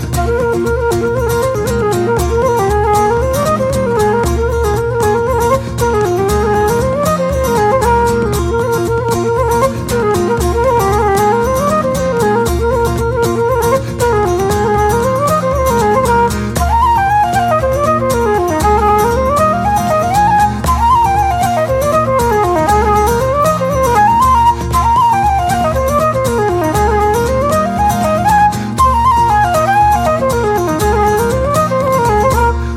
guitar
Melodeon
Bodhran, percussion
Cello